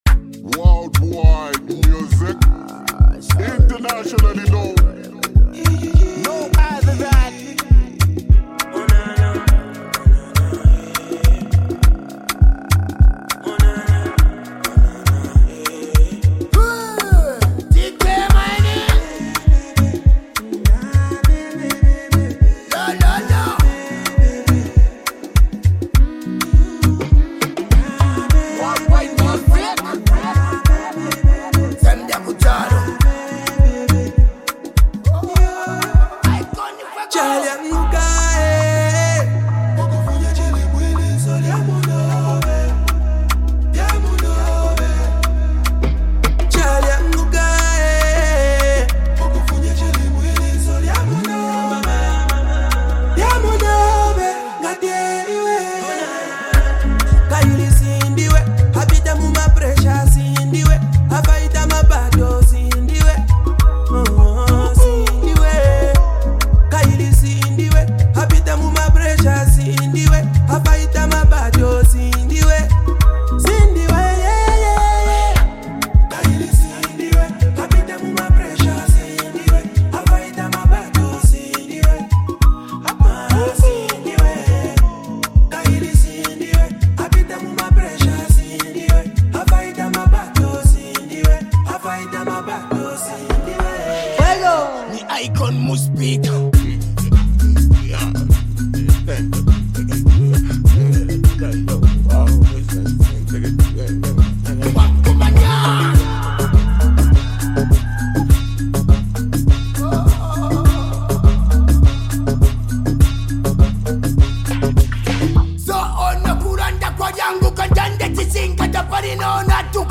soulful, emotional chorus